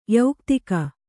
♪ yauktika